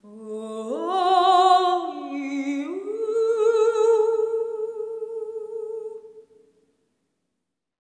ETHEREAL03-L.wav